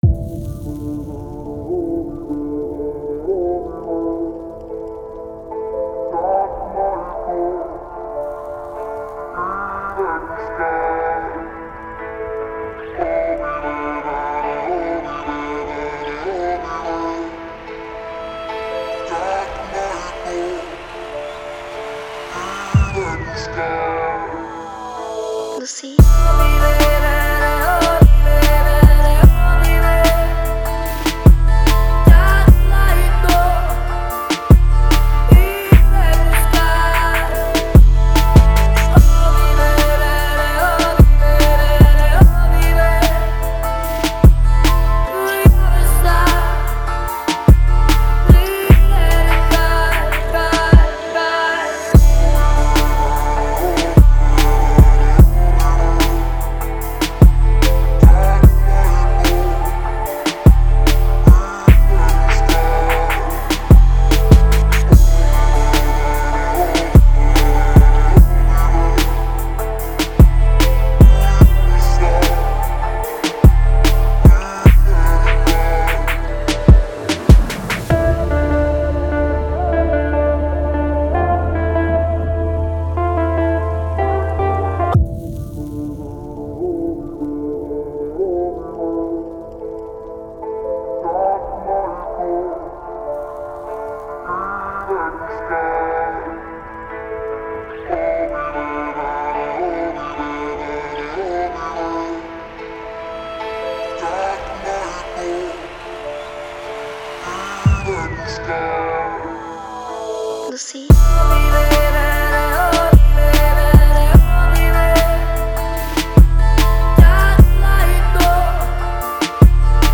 который сочетает в себе элементы электронной музыки и попа.